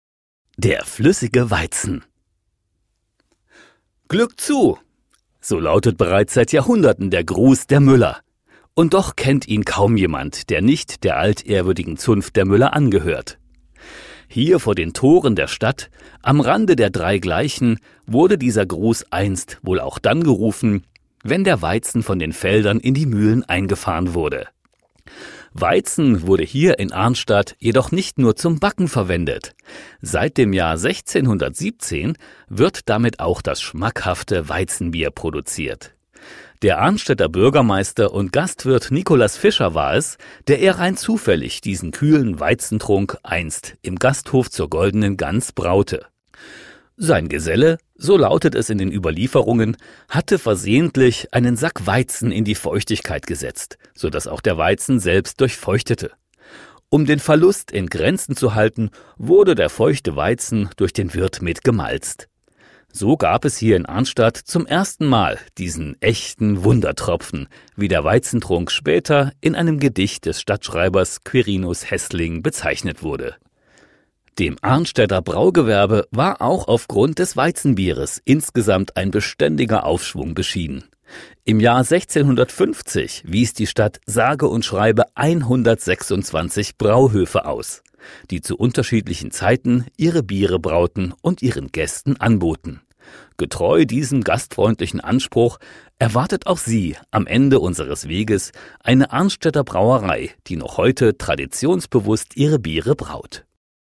Audio-Guide